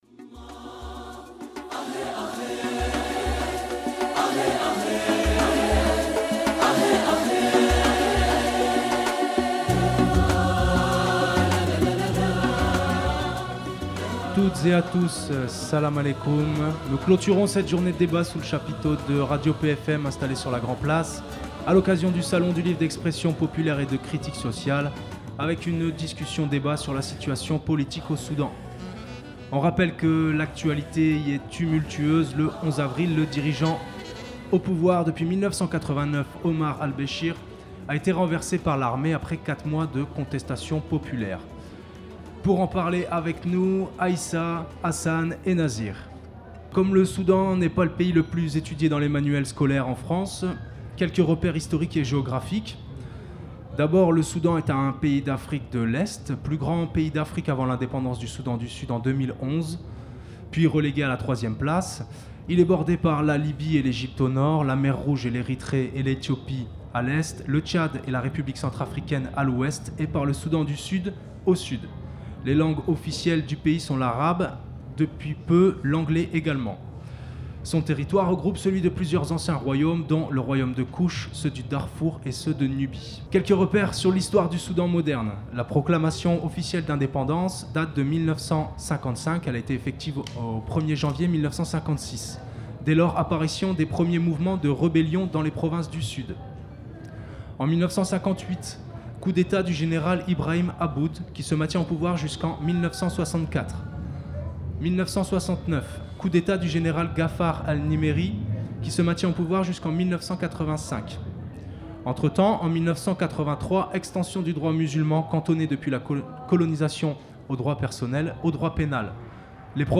Discussion avec des réfugiés soudanais, sur la situation politique actuelle dans leur pays (soulèvement populaire et renversement du dictateur en place Omar Al Beshir par l’armée), lors du salon du 1er mai à Arras.